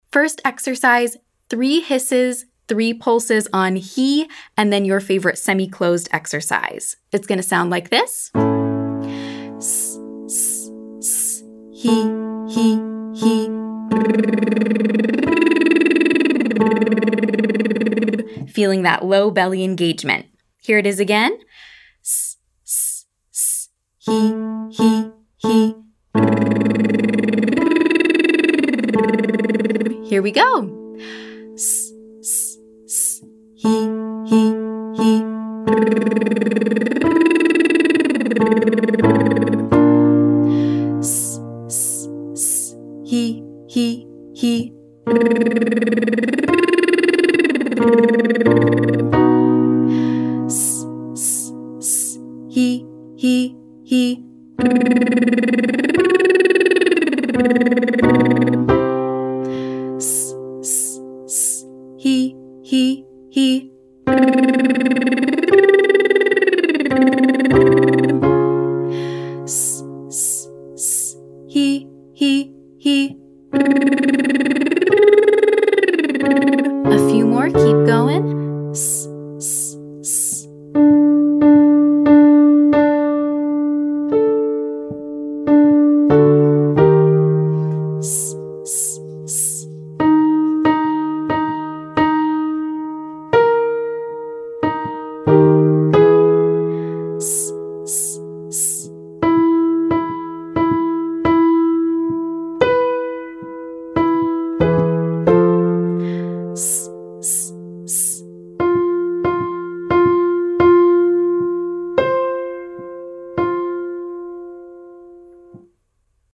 Quick warmup
Exercise 1: Hiss pulse x3, Hee x3, SOVT 151